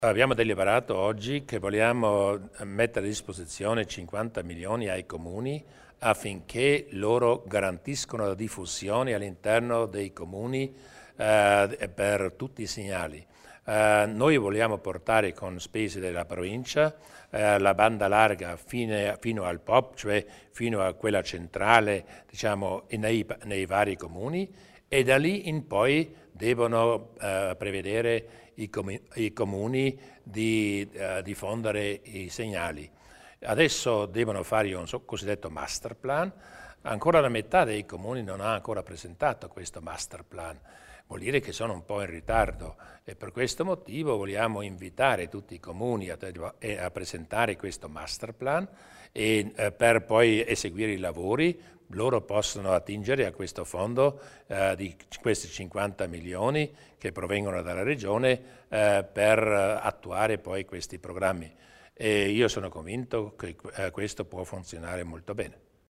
Il Presidente Durnwalder spiega gli investimenti per la banda larga